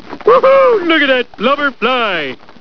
blubber.wav